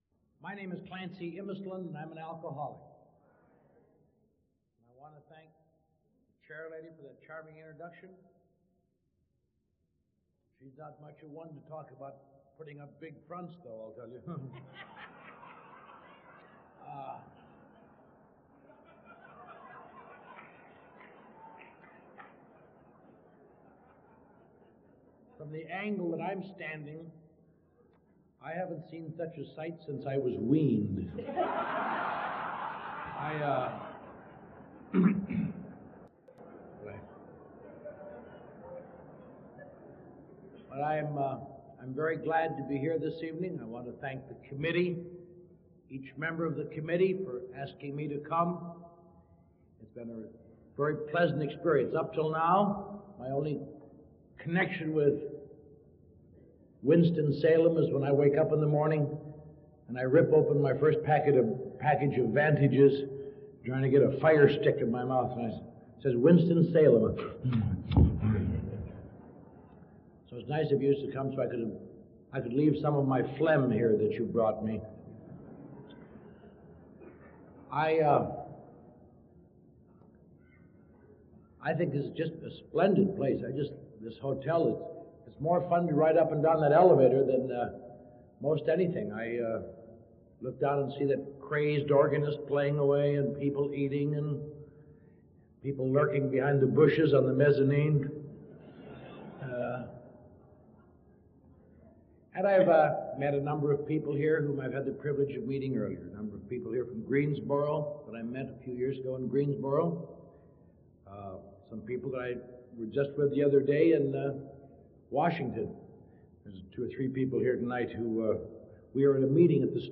Alcoholics Anonymous Speaker Recordings
(note: volume improves after 30 seconds)